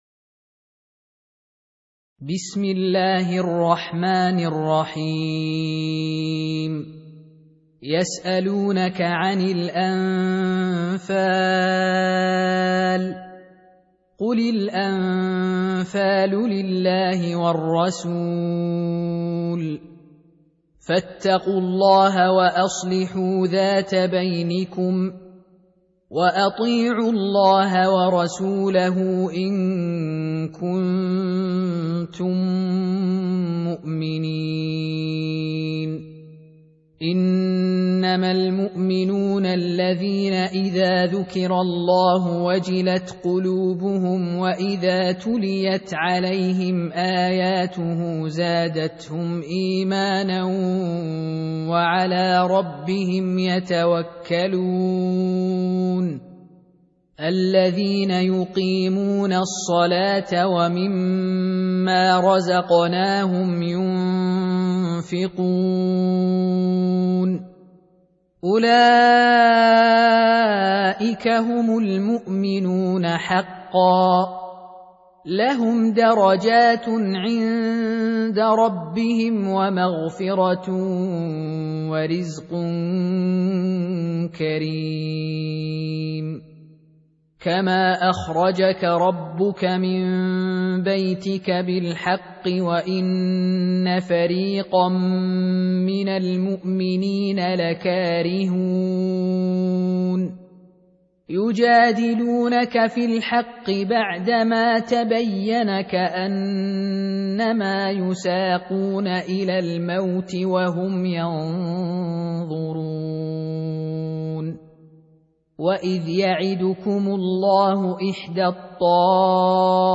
Surah Repeating تكرار السورة Download Surah حمّل السورة Reciting Murattalah Audio for 8. Surah Al-Anf�l سورة الأنفال N.B *Surah Includes Al-Basmalah Reciters Sequents تتابع التلاوات Reciters Repeats تكرار التلاوات